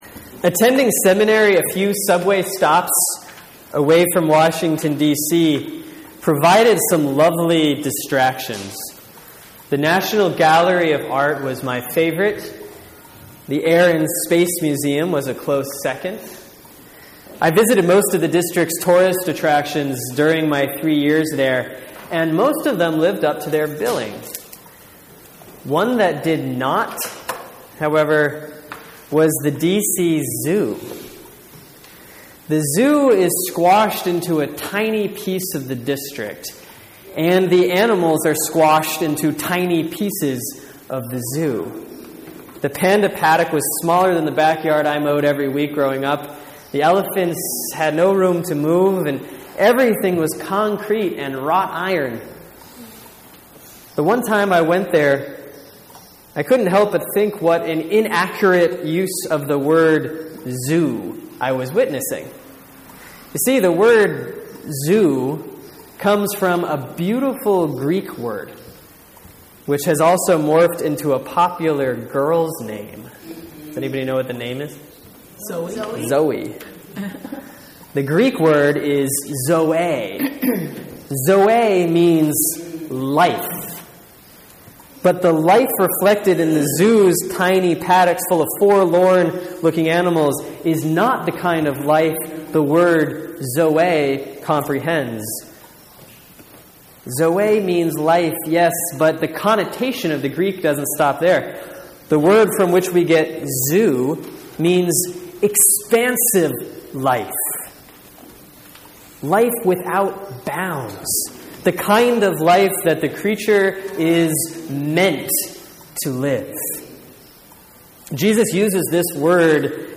Sermon for Sunday, May 18, 2014 || Easter 5A || John 14:1-14